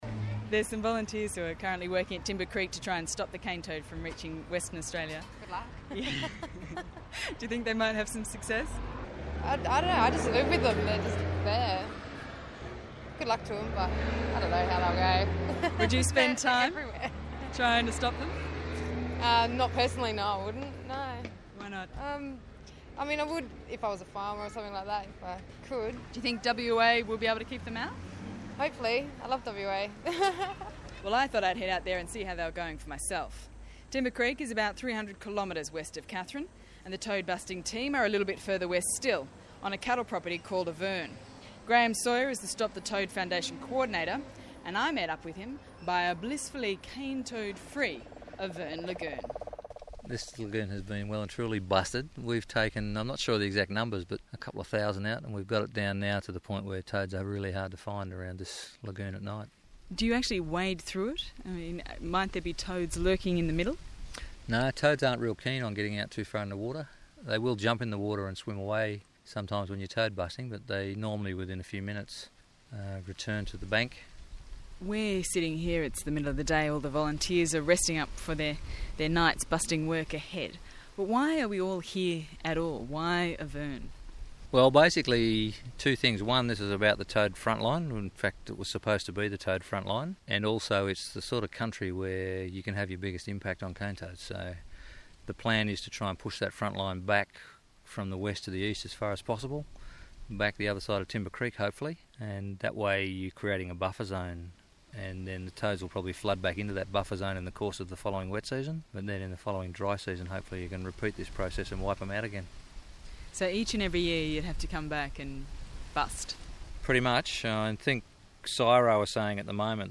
reports on the Great Toad Muster for Radio National's Bush Telegraph